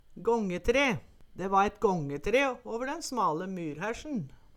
gångetre - Numedalsmål (en-US)